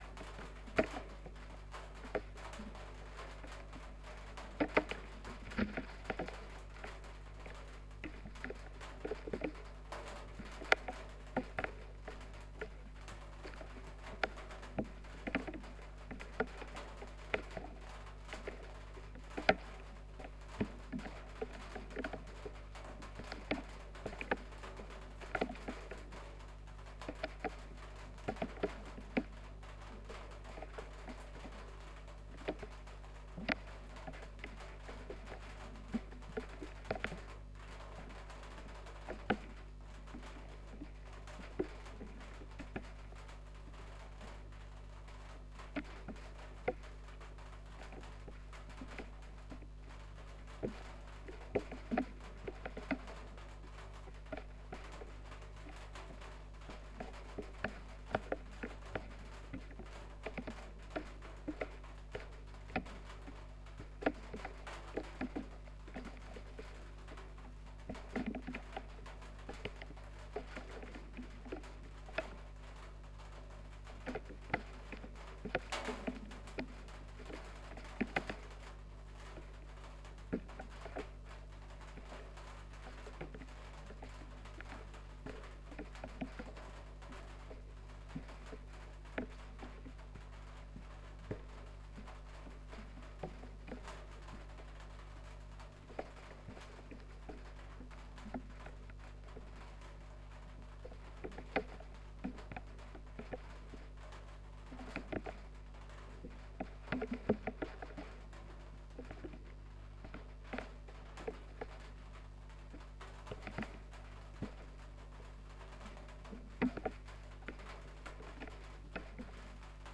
MAM VF11 Vocoder
über die Pre-Fader Aux werden die Audio Signale im Vocoder gemischt.
Tascam HD-P2 (24 Bit, 48 kHz) mit Rode NT4 Stereo Kondensator Mikrofon
6 Kanal Audio im Raum jeder der 3 Mixer wird getrennt verstärkt über 6 im Raum aufgestellte Boxen wiedergegeben alle Audio-Kanäle mit Audacity auf -1 dB normalisiert.
01 Soundcheck (6 Kanal stereo).mp3